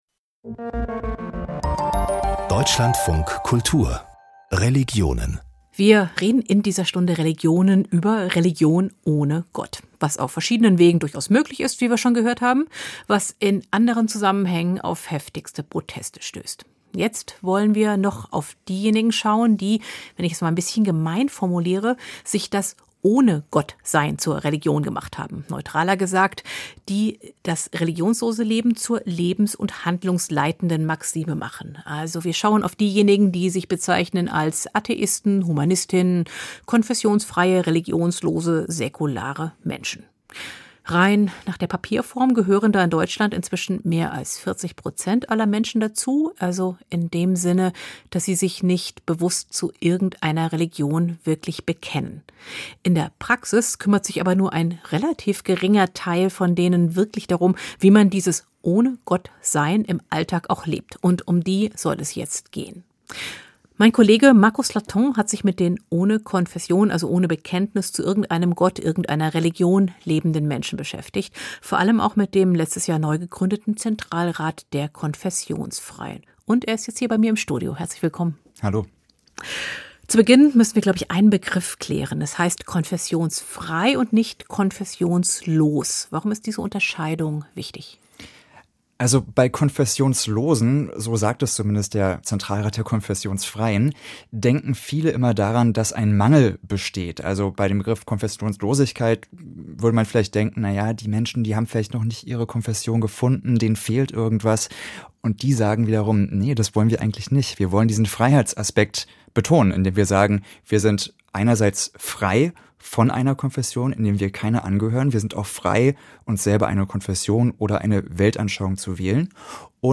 Kulturnachrichten - 16.10.2022